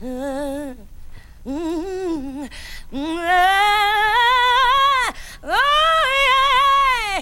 WHO...YEAH.wav